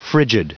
Prononciation du mot frigid en anglais (fichier audio)
Prononciation du mot : frigid